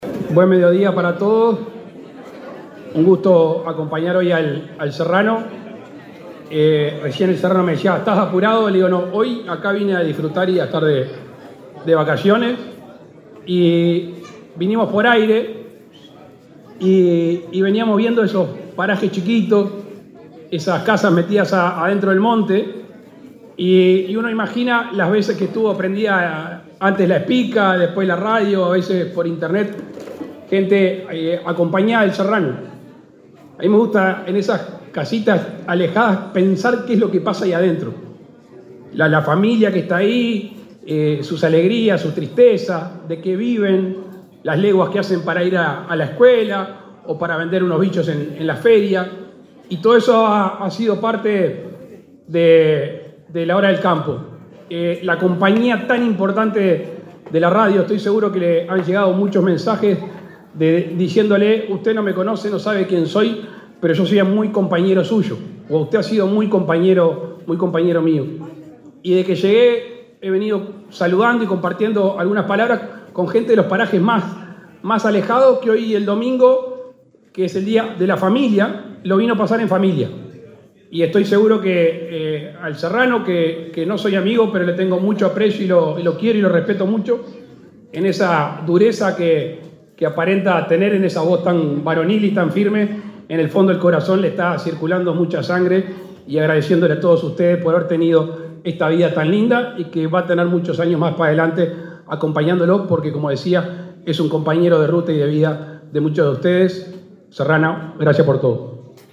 Palabras del presidente Luis Lacalle Pou
El presidente Luis Lacalle Pou participó, este domingo 18 en Cerro Largo, del festejo por el 55.° aniversario del programa radial Hora de Campo, que